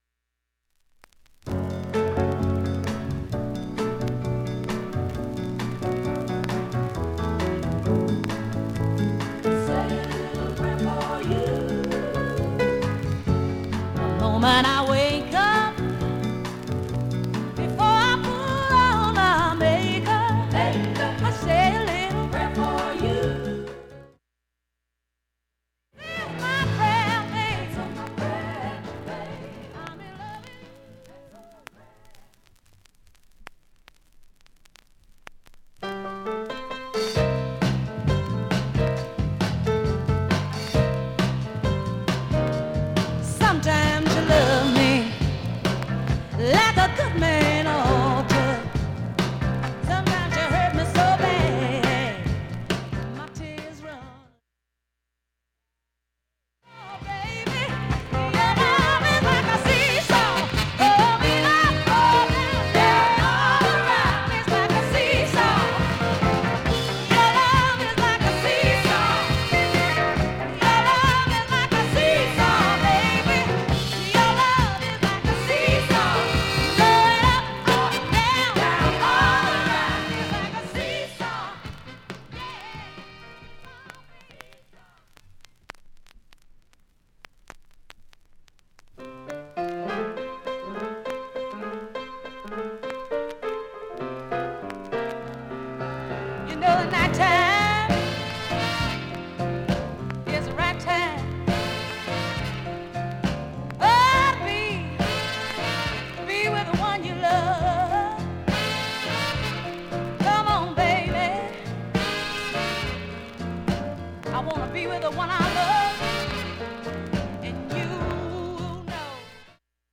プツ音自体も無音部でもかすかでストレスは
A-1B-1始めかすかなチリ(3m34s〜)
ほか５回までのかすかなプツが１箇所
単発のかすかなプツが３箇所